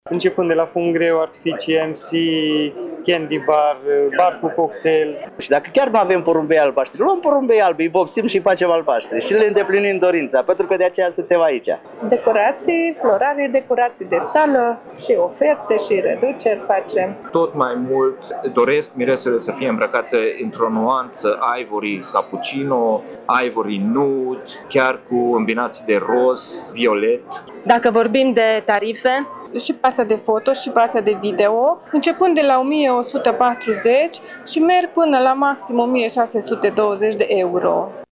Expoziția Nunta Noastră și-a deschis porțile în Cetatea Tîrgu Mureș cu toată gama de servicii și produse specifice petrecerilor.
Specialiștii spun că anul acesta cheltuielile mirilor pentru nuntă vor fi între 200 și 300 de lei de invitat. Așa că și-au pregătit cele mai bune argumente pentru a atrage clienții și a face față concurenței din domeniu: